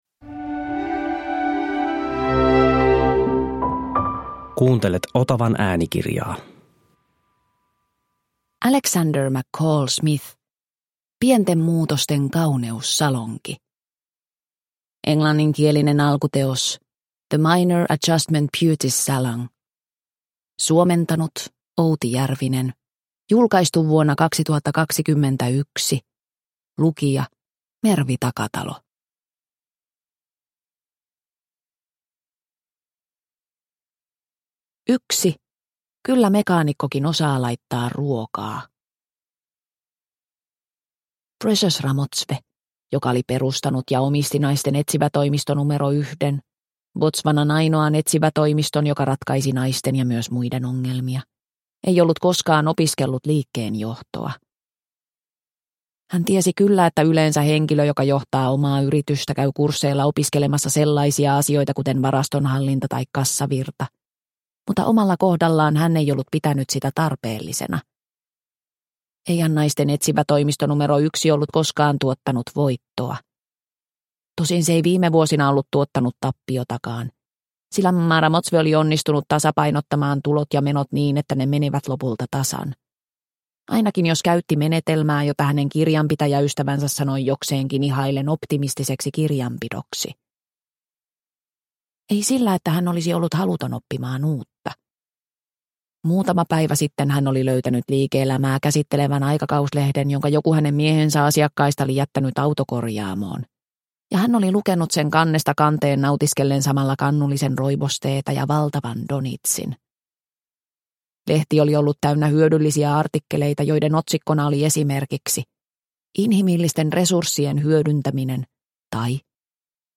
Pienten muutosten kauneussalonki – Ljudbok – Laddas ner